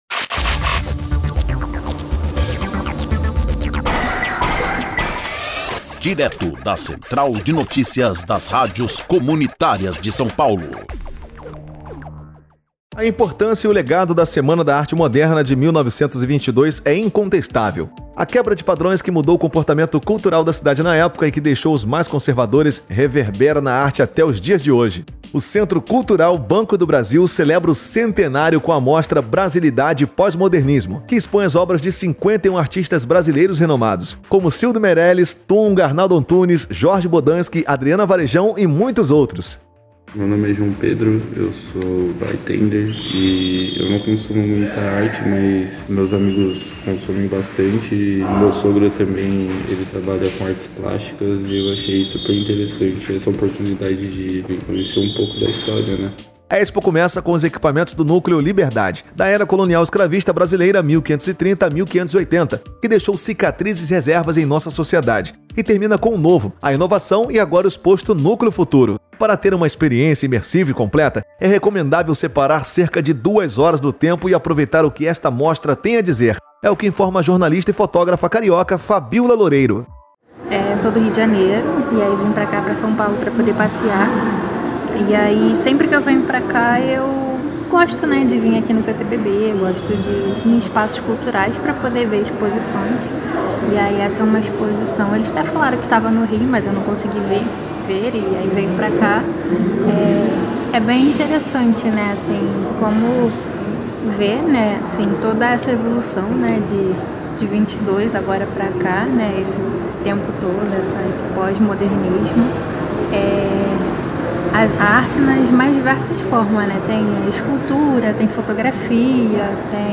3-NOTICIA-BRASILIDADE-POS-MODERNISMOCENTRO-CULTURAL-BANCO-DO-BRASIL-LIBERTACAO.mp3